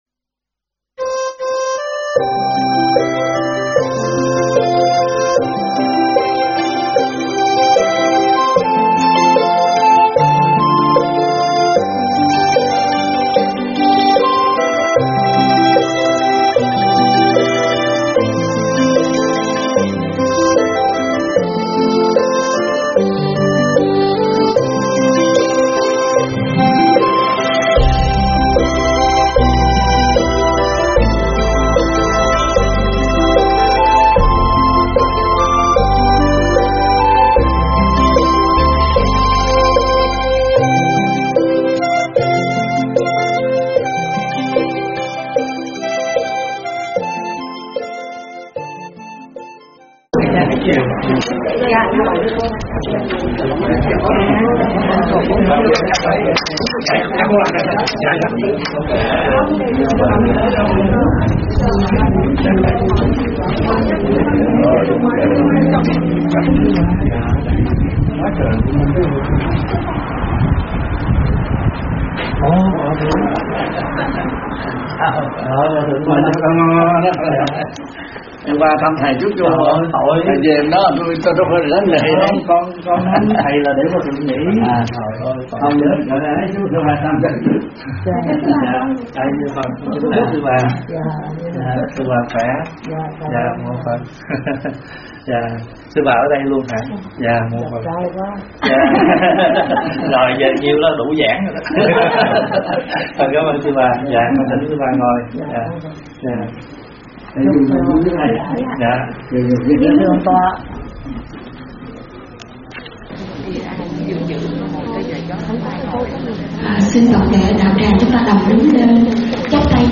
thuyết pháp Tình Thương Rộng Lớn
tại Tv. Đại Bi, Garden Grove